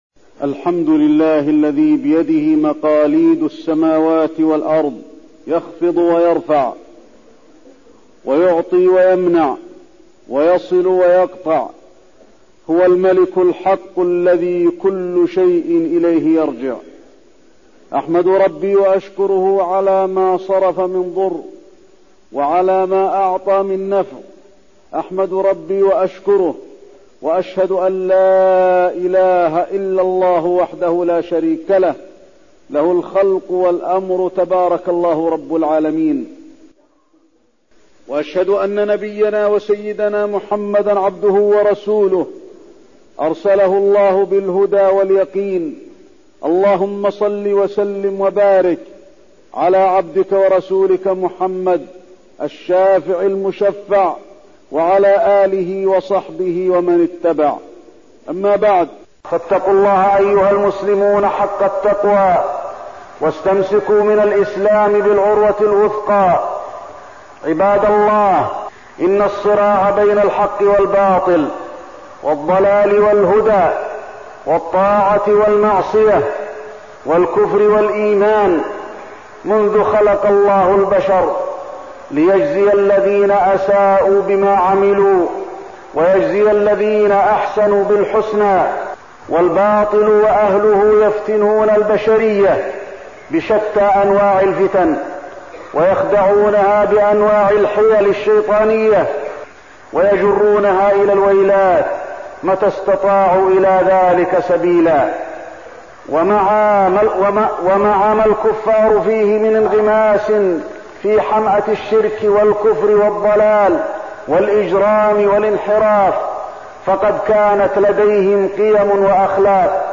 تاريخ النشر ٢٦ ربيع الأول ١٤١٥ هـ المكان: المسجد النبوي الشيخ: فضيلة الشيخ د. علي بن عبدالرحمن الحذيفي فضيلة الشيخ د. علي بن عبدالرحمن الحذيفي مؤتمر السكان والصراع بين الحق والباطل